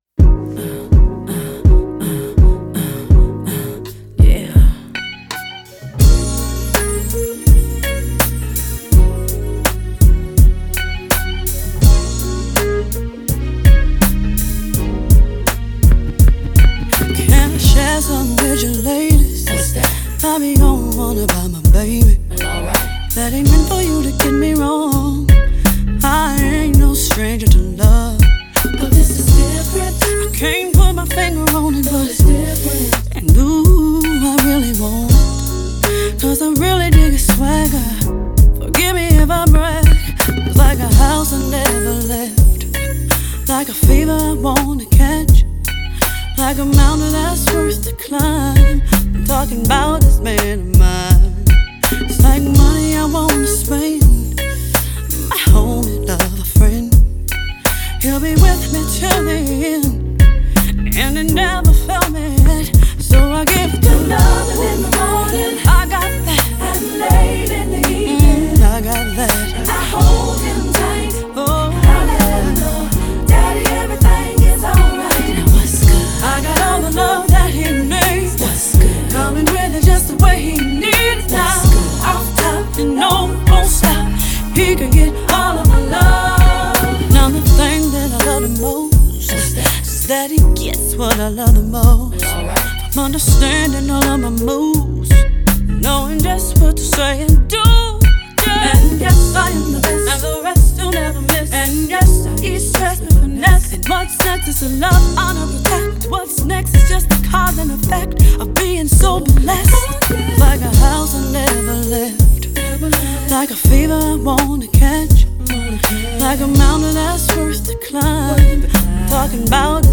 la chanteuse R'n'B